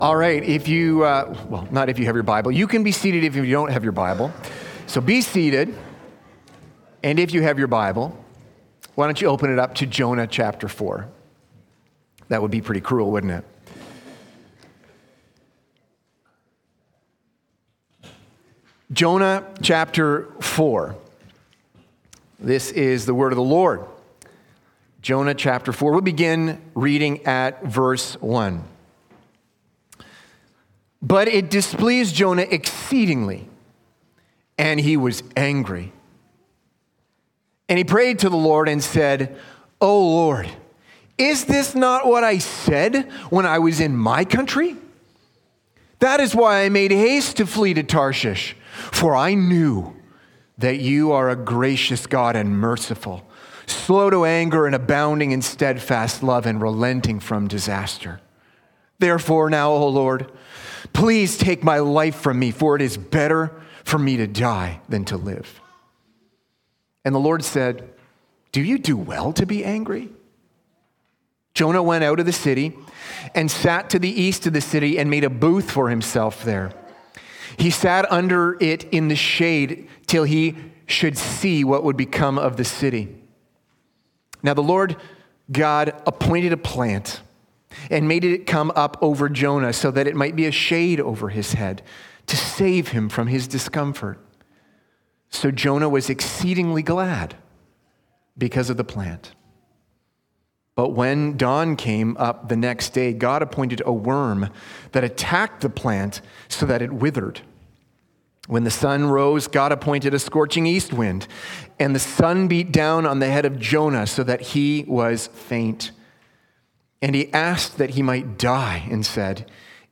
Sermons | Park City Gospel Church